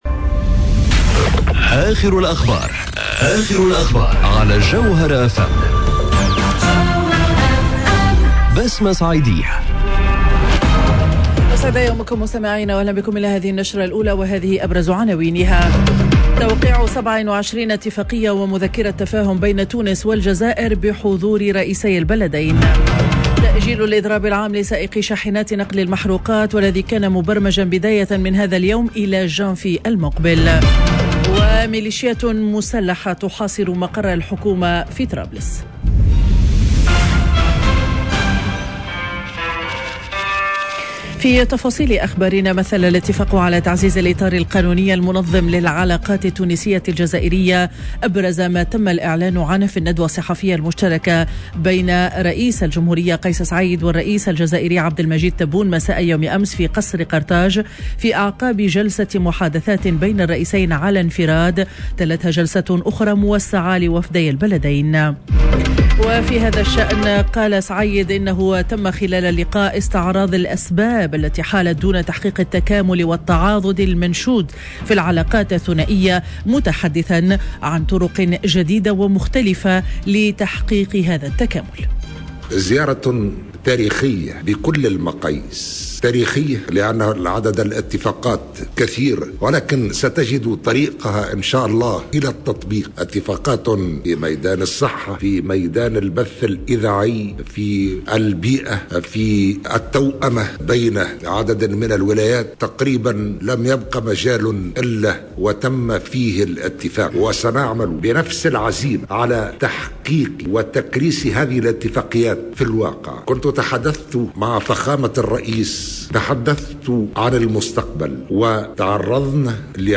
نشرة أخبار السابعة صباحا ليوم الخميس 16 ديسمبر 2021